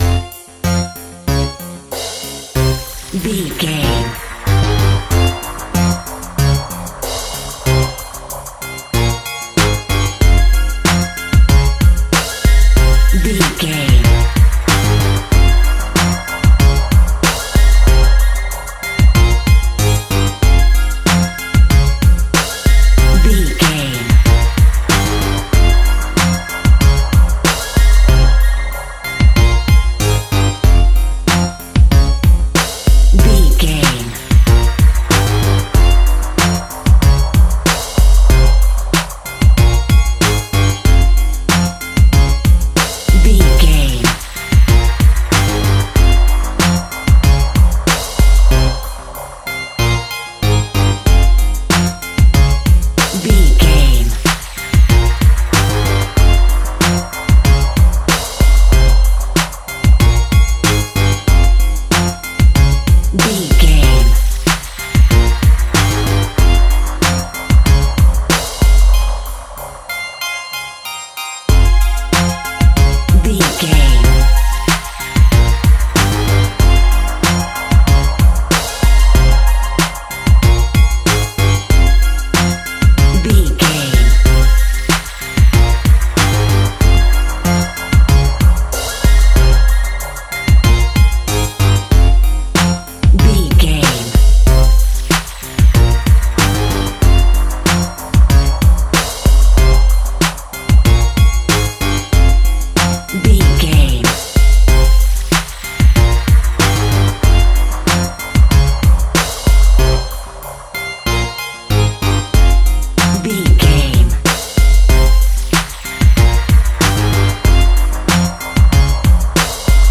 Aeolian/Minor
drum machine
synthesiser
hip hop
Funk
neo soul
acid jazz
confident
energetic
bouncy
funky